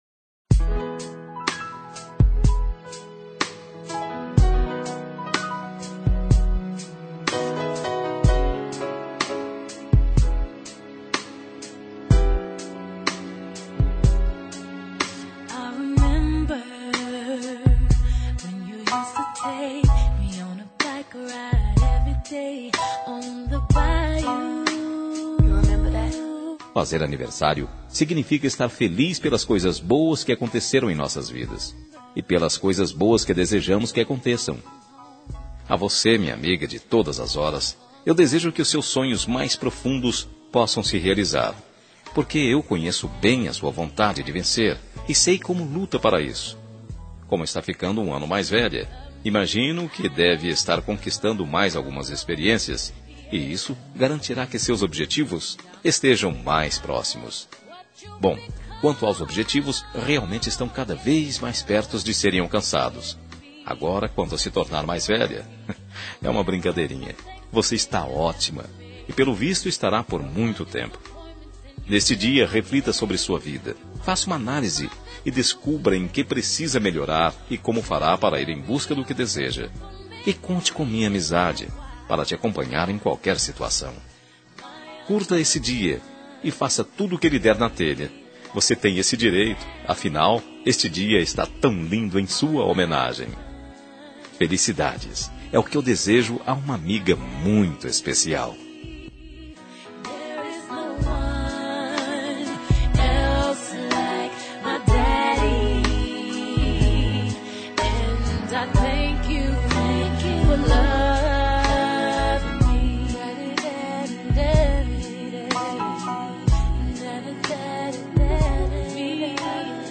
Telemensagem Aniversário de Amiga – Voz Masculina – Cód: 1586